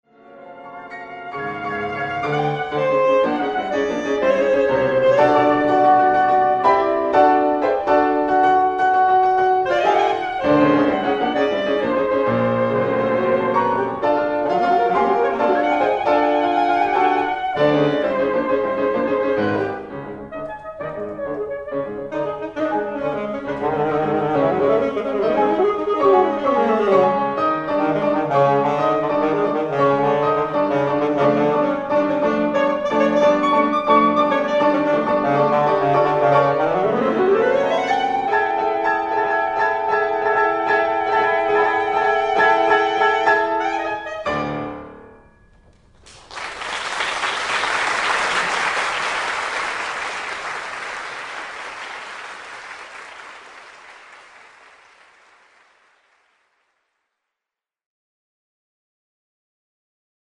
saxophone
piano
LIVE